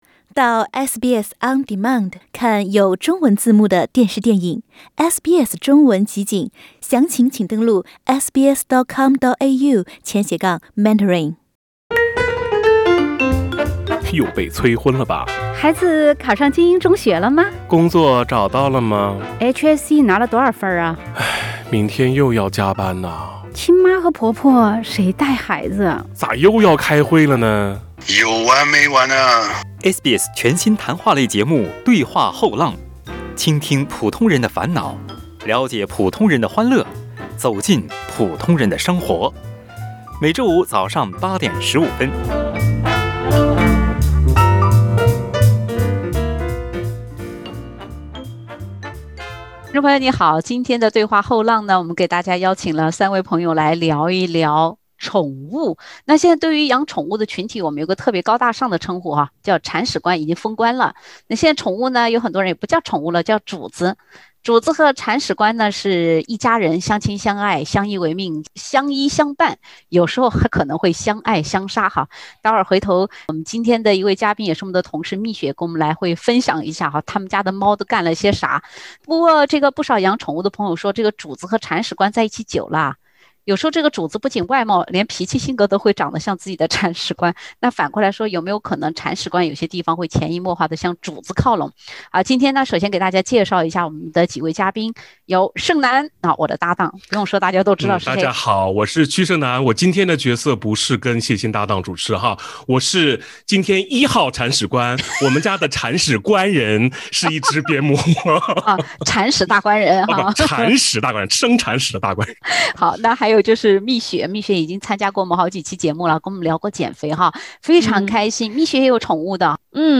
欢迎收听澳大利亚最亲民的中文聊天类节目-《对话后浪》。